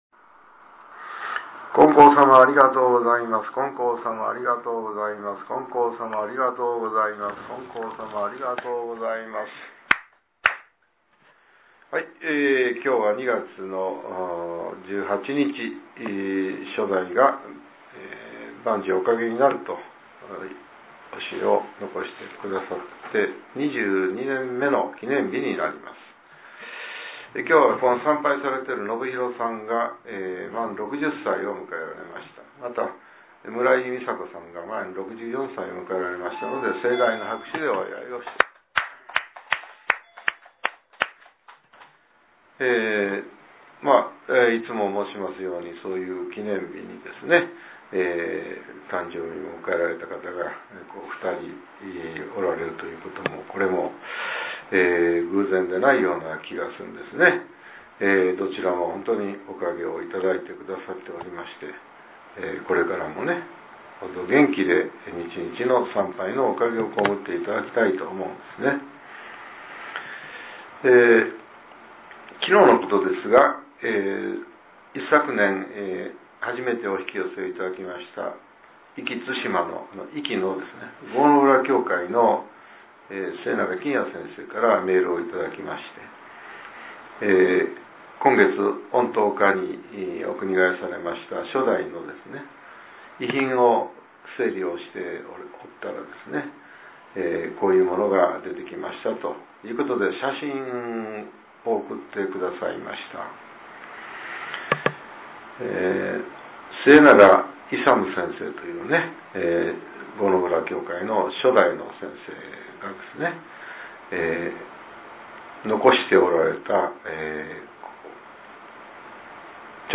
令和７年２月１８日（朝）のお話が、音声ブログとして更新されています。